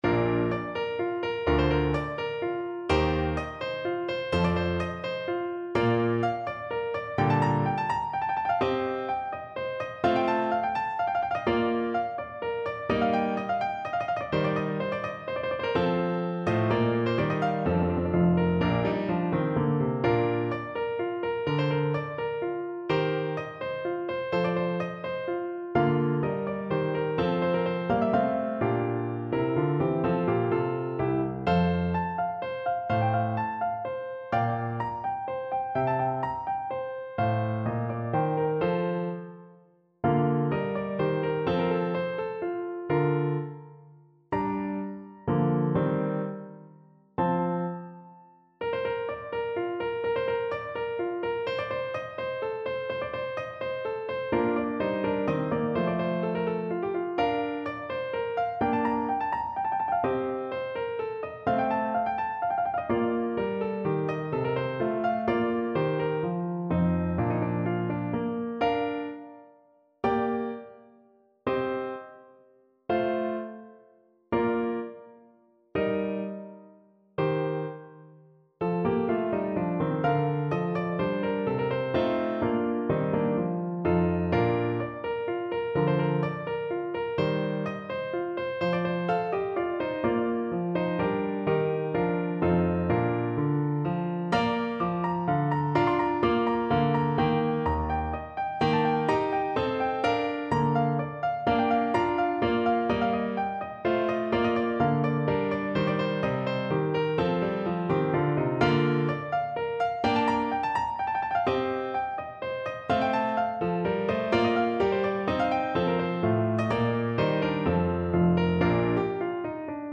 = 126 Allegro (View more music marked Allegro)
3/4 (View more 3/4 Music)
Soprano Voice  (View more Advanced Soprano Voice Music)
Classical (View more Classical Soprano Voice Music)